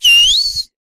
一系列的哨声
描述：Tascam DR05录制的系列口哨
标签： 哨子 fischio fischi 口哨
声道立体声